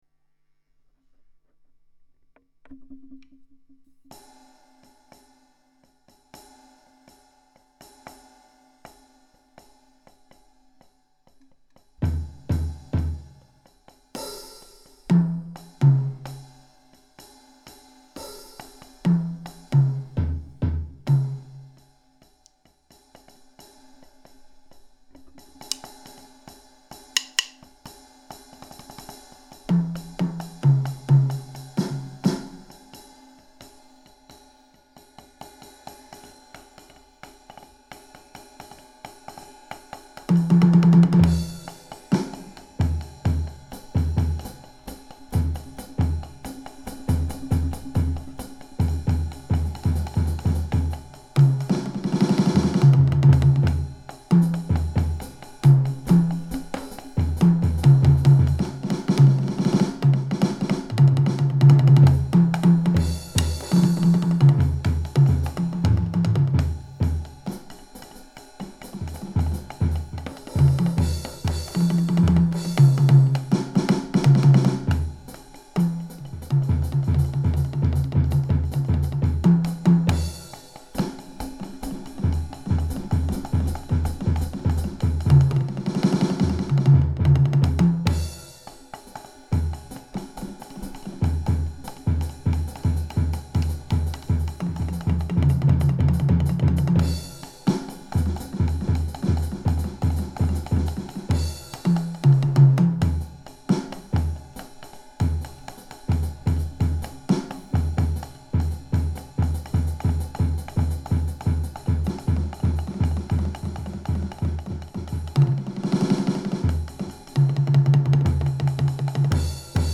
Essais percussifs